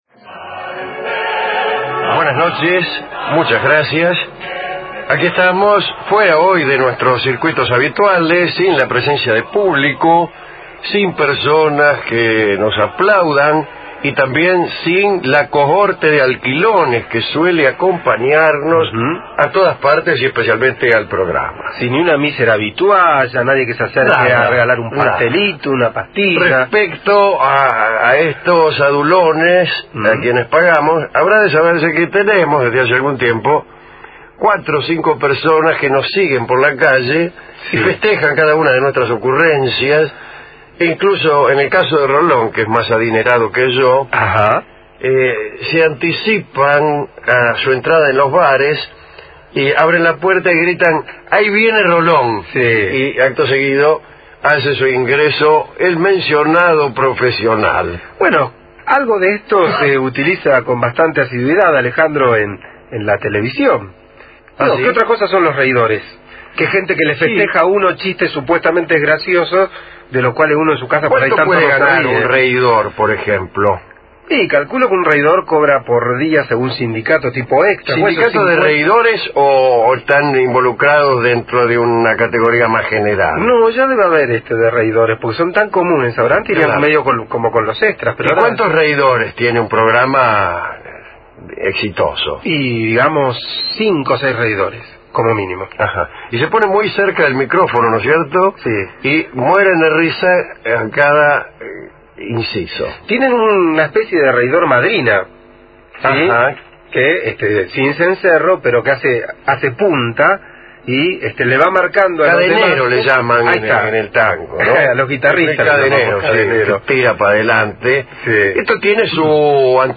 9 de Junio de 2007 La Venganza será Terrible del 09/06/2007 mp3 player transcripción Estudio Radio 10 Alejandro Dolina, Gabriel Rolón, Gillespi Segmento Inicial Haciendo televisión por radio ¿Donde termina Uriarte?